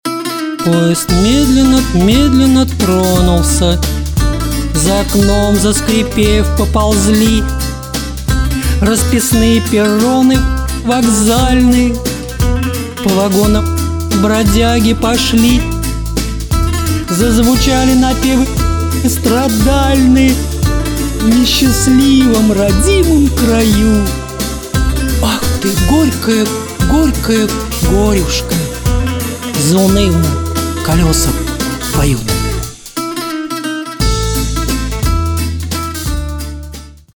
Домашняя студия. Оцените результат.
Писать баян можно. 14 квадратов - это все-таки маленькая комната и проблемы ниже 200 Гц в ней будут по-любому.